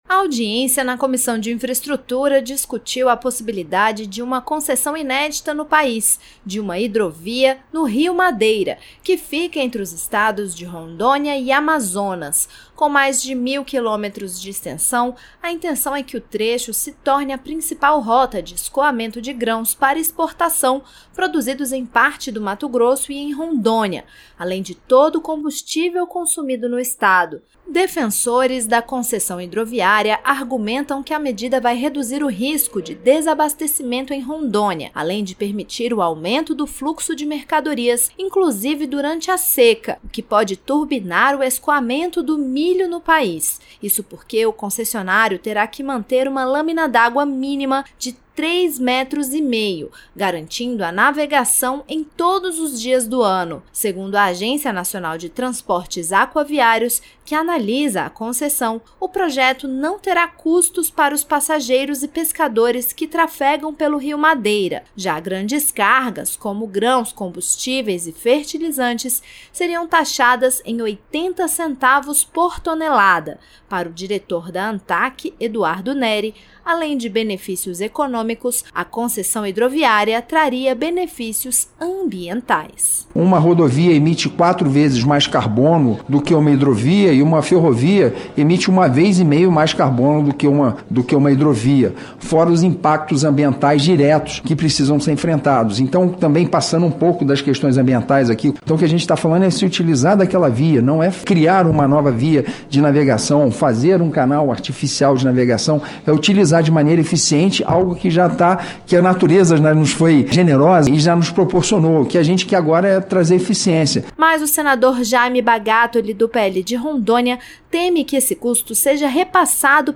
Durante audiência pública promovida nesta terça-feira (10) pela Comissão de Infraestrutura (CI), parlamentares, representantes do governo e empresários defenderam a concessão de uma hidrovia para o escoamento da produção de grãos no Rio Madeira, entre os estados de Rondônia e Amazonas.